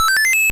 Success sound effect from Super Mario Bros. 3